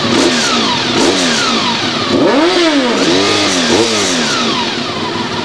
Slicks Racing Team, les sons moteurs
Allez, dites moi de quel moteur provient ce superbe son:
vrooom.wav